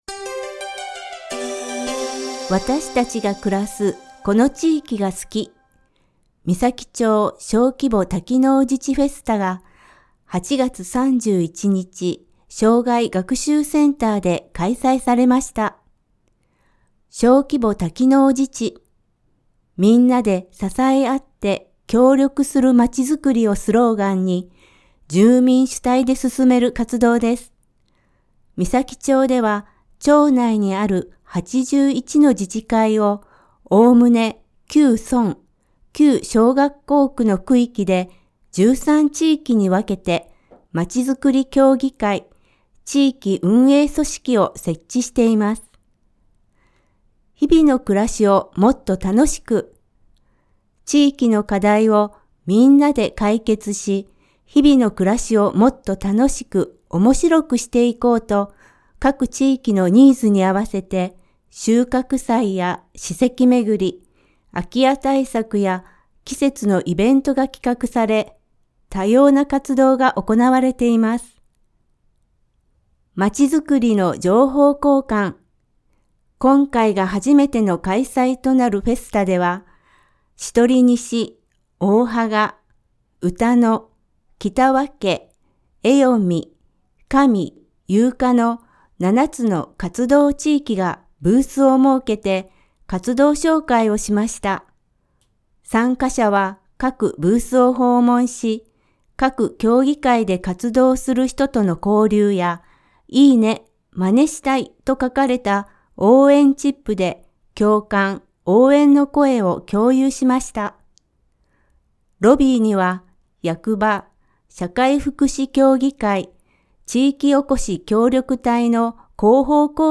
声の広報（広報紙の一部を読み上げています）